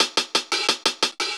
Index of /musicradar/ultimate-hihat-samples/175bpm
UHH_AcoustiHatA_175-01.wav